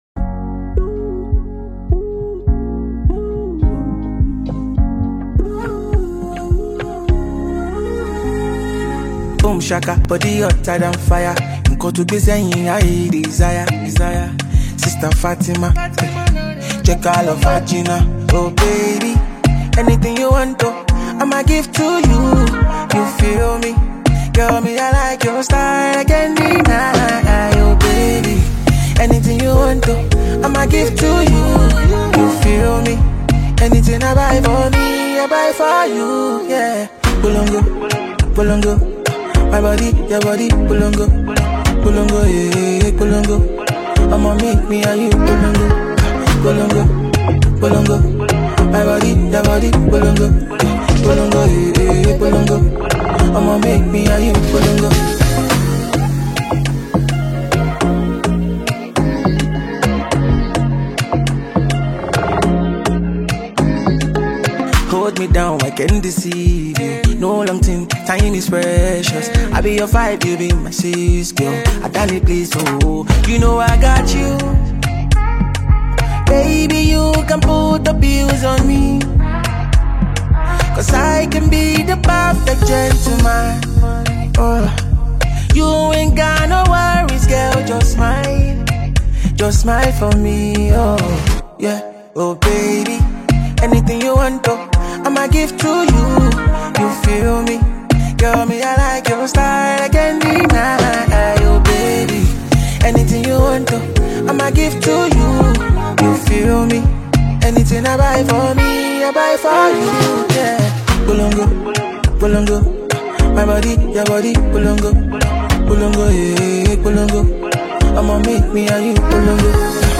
Nigerian Afro-pop sensation